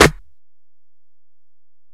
Snare (38).wav